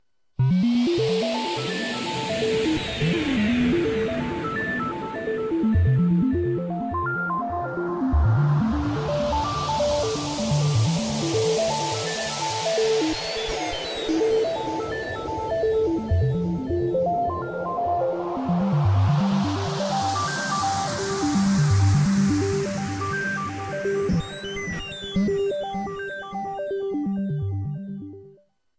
Sonidos Alienígenas V.1: Efectos de Sonido Sin Copyright para Aventuras Intergalácticas
Esta colección incluye una variedad de sonidos alienígenas, desde extrañas vocalizaciones y zumbidos misteriosos hasta ruidos de naves espaciales y tecnología alienígena. Su diseño sonoro inmersivo y futurista te permitirá crear experiencias auditivas únicas y memorables.
• Diseño sonoro futurista: Sonidos inmersivos y de alta calidad.
Sonidos alienígenas, efectos de sonido sin copyright, sonidos extraterrestres, audio de ciencia ficción, sonidos de naves espaciales, sonidos futuristas, descarga gratuita.
Tipo: sound_effect
Musica alienigena.mp3